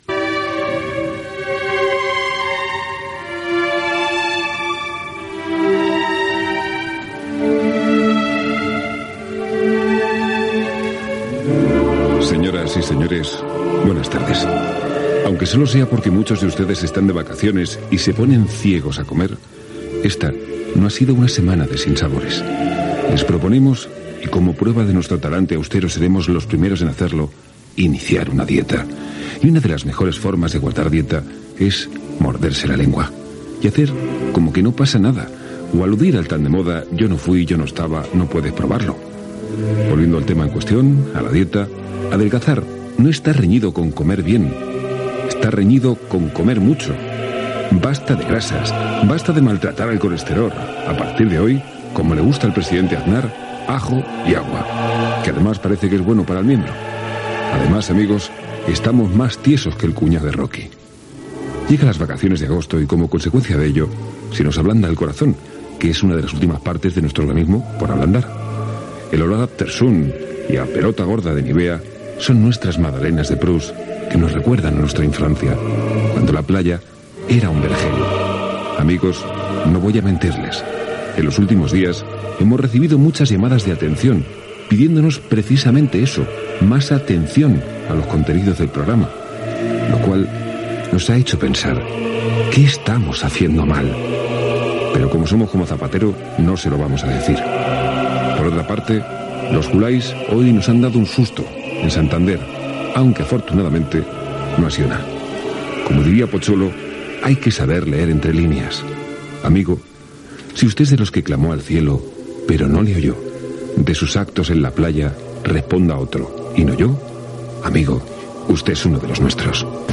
Inici del programa i comentari sobre fer dieta. Fragment de la pel·lícula "Uno de los nuestros".
Entreteniment